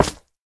Minecraft Version Minecraft Version 1.21.5 Latest Release | Latest Snapshot 1.21.5 / assets / minecraft / sounds / block / froglight / break2.ogg Compare With Compare With Latest Release | Latest Snapshot